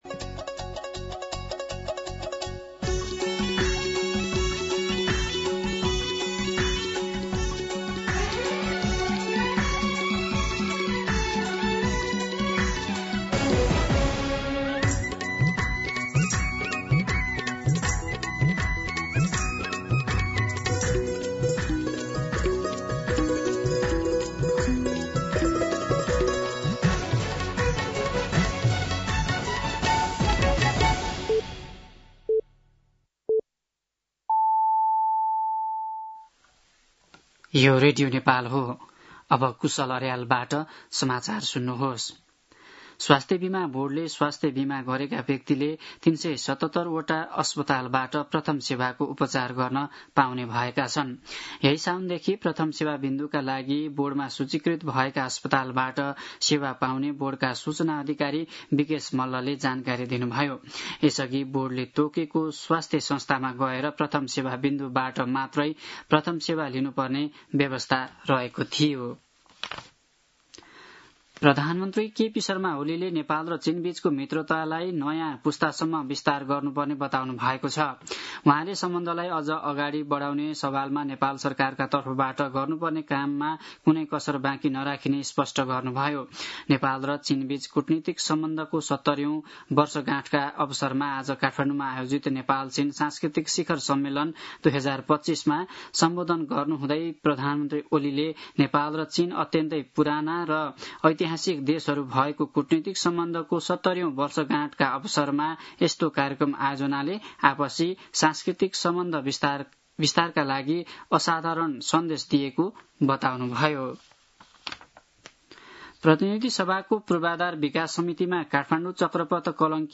दिउँसो ४ बजेको नेपाली समाचार : २ साउन , २०८२